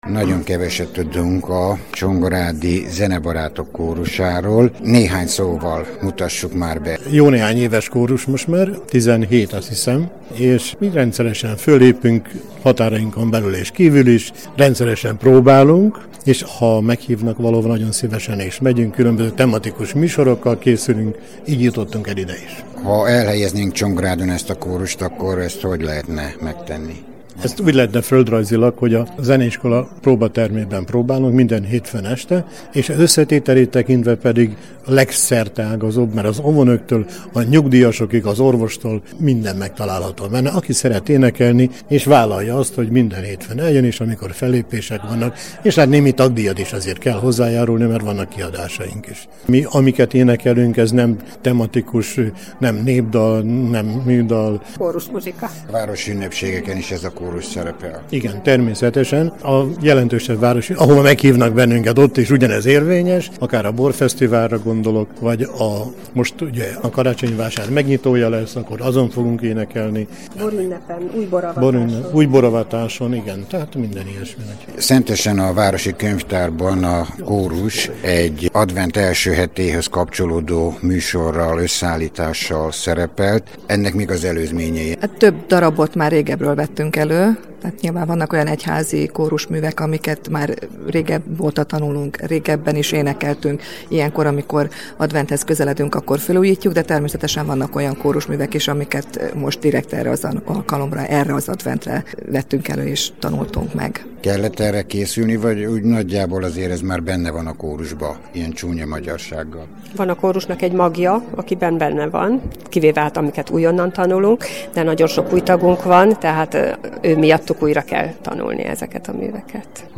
Aufnahme in Rádió 451 (Szentes), 01.12.2014
Beszélgetés a Szentes Rádió - (3.3 MB, MP3)